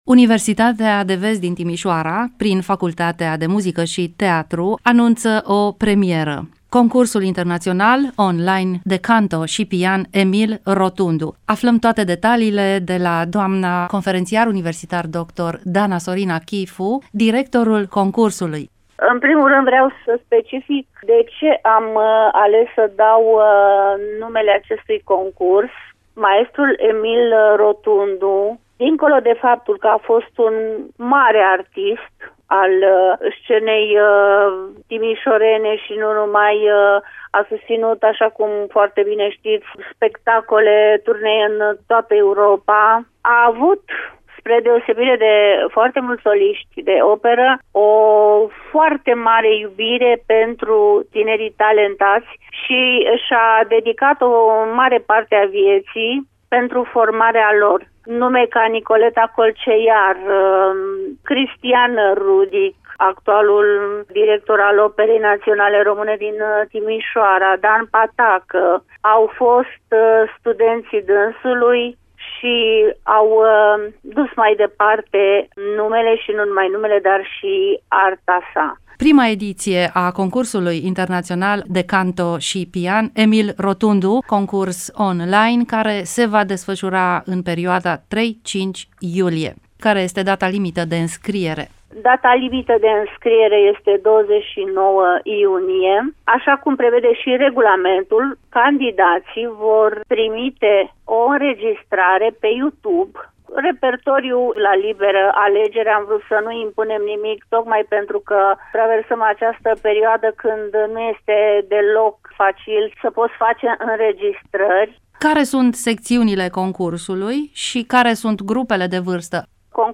Detalii în dialogul